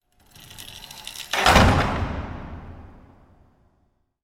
Doors
DoorWeightedClose.wav